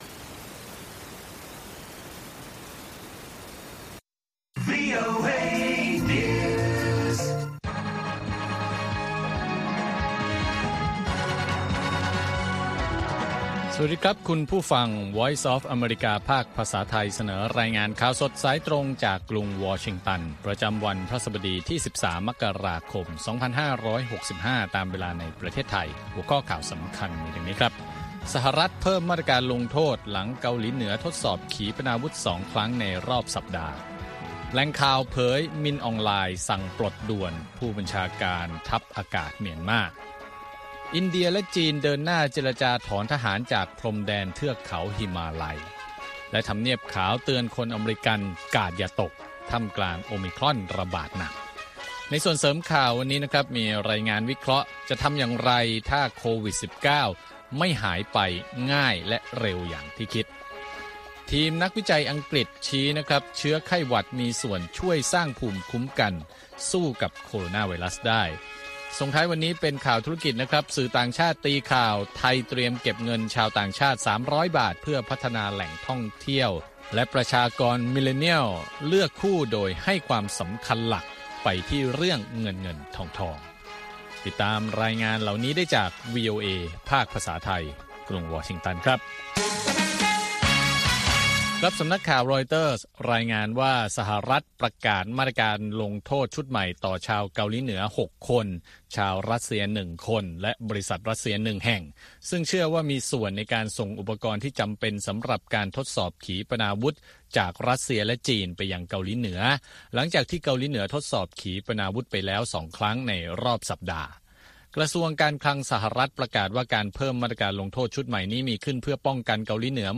ข่าวสดสายตรงจากวีโอเอ ภาคภาษาไทย ประจำวันพฤหัสบดีที่ 13 มกราคม 2565 ตามเวลาประเทศไทย